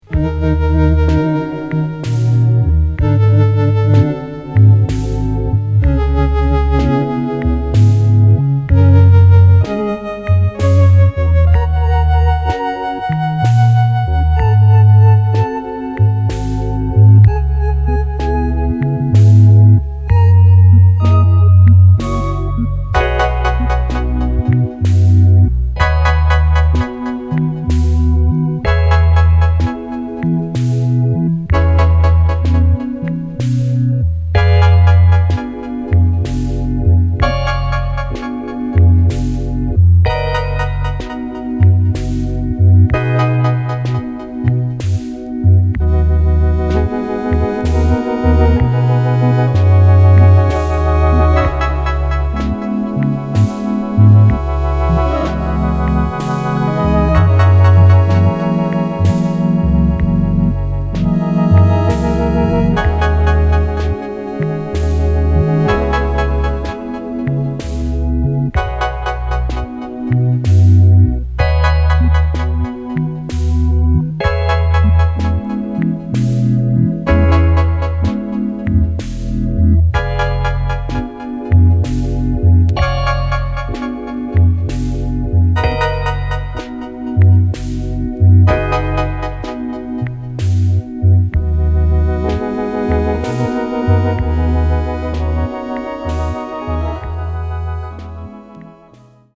星空をひろげたような夢見心地の煌めきを放つムーグラウンジずらり。
電子音　ライブラリー　ラウンジ